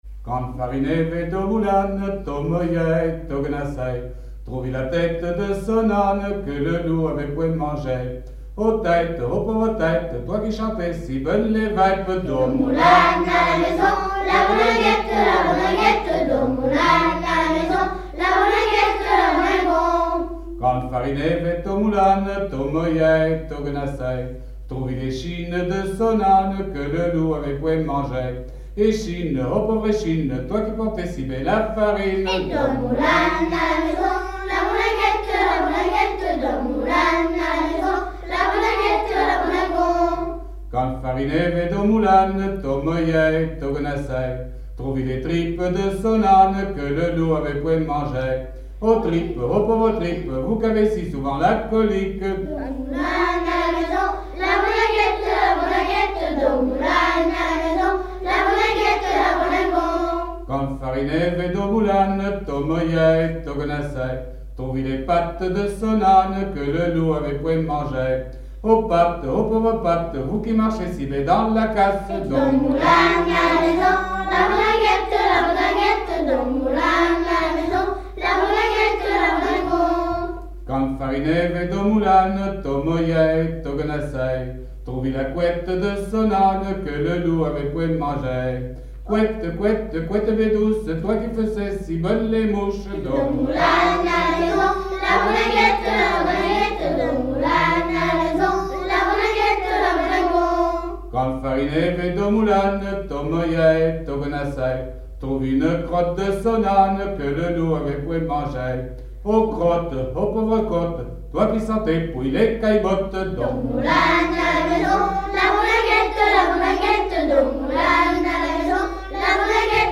Genre énumérative
Enquête EthnoDoc et Arexcpo dans le cadre des activités courantes des membres des associations
Catégorie Pièce musicale inédite